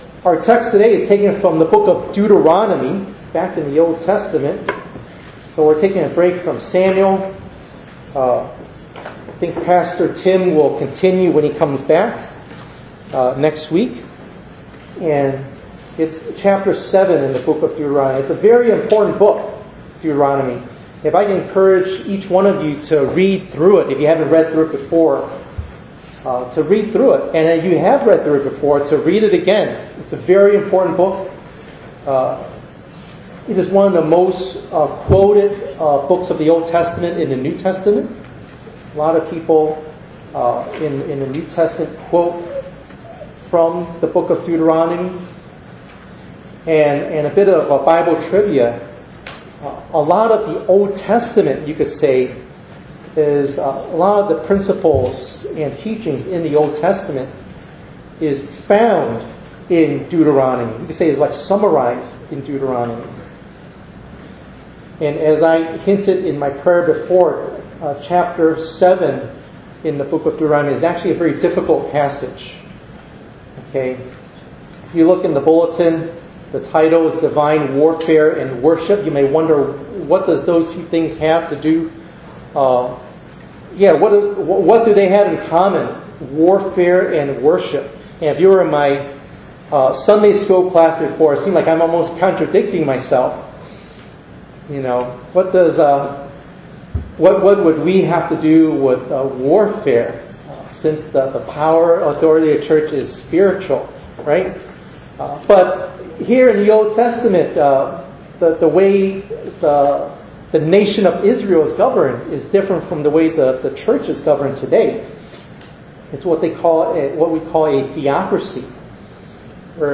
7_6_25_ENG_Sermon.mp3